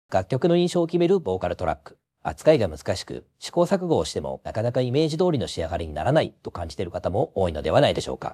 ▼上記をボイスパック（My Voice）に差し替えた音声